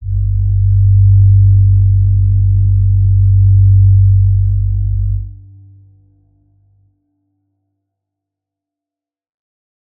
G_Crystal-G2-mf.wav